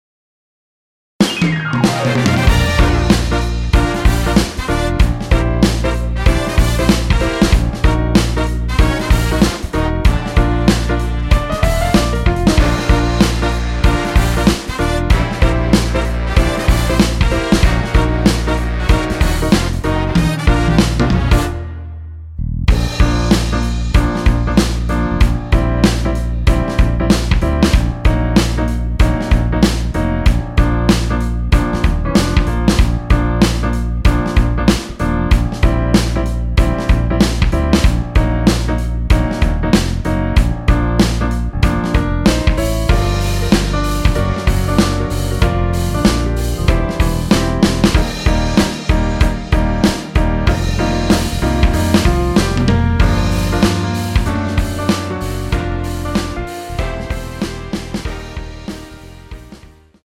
원키에서(-4)내린 MR입니다.
Bb
앞부분30초, 뒷부분30초씩 편집해서 올려 드리고 있습니다.
중간에 음이 끈어지고 다시 나오는 이유는